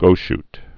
(gōsht)